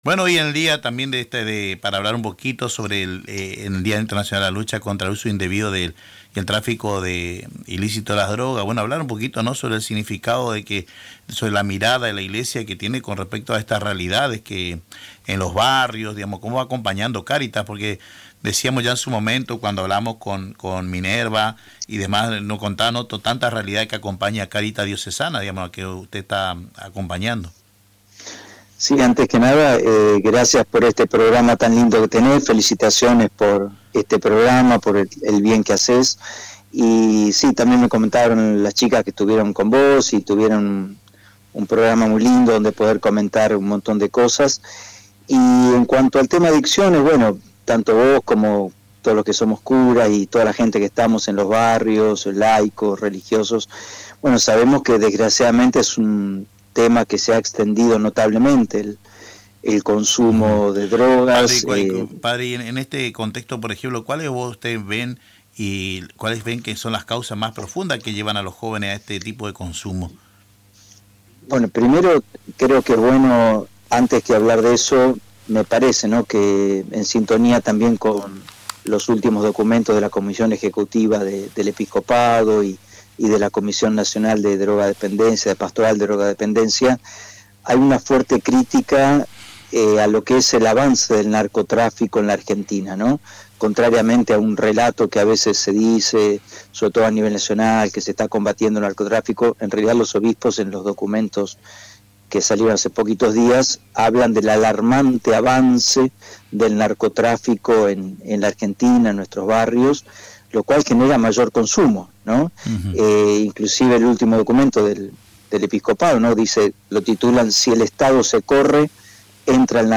Fue entrevistado en el programa “Caminando juntos” de Radio Tupambaé, donde analizó la mirada de la Iglesia sobre las adicciones y la urgencia de generar entornos de contención para niños y jóvenes.